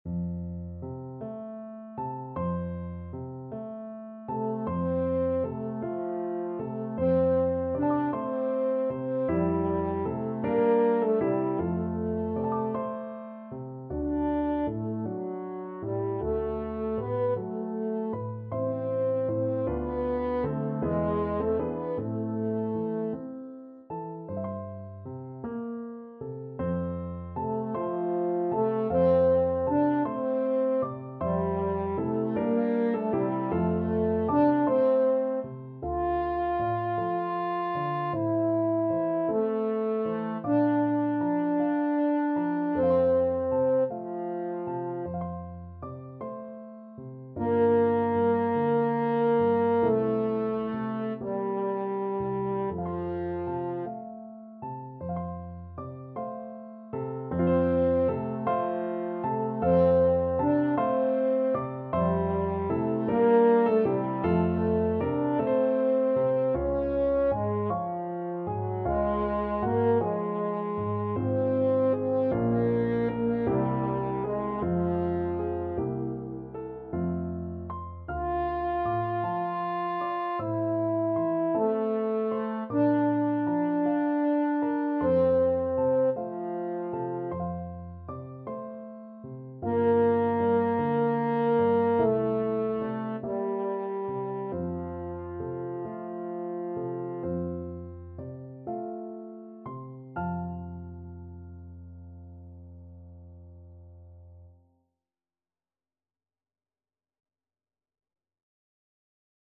Free Sheet music for French Horn
French Horn
F major (Sounding Pitch) C major (French Horn in F) (View more F major Music for French Horn )
6/8 (View more 6/8 Music)
~. = 52 Allegretto
Classical (View more Classical French Horn Music)